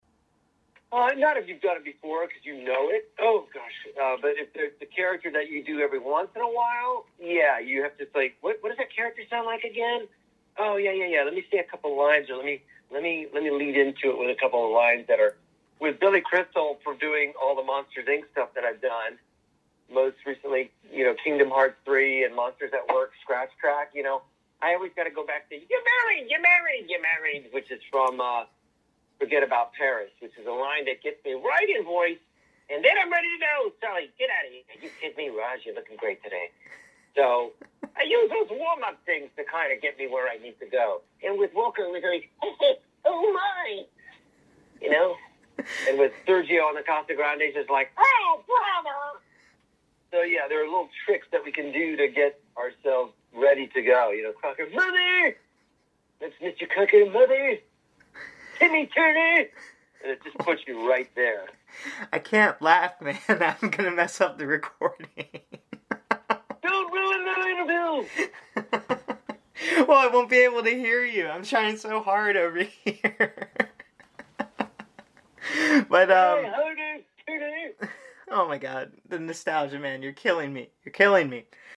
Interview With Carlos Alazraqui, Co-Writer And Star Of WITNESS INFECTION
CarlosA_InterviewSegment.mp3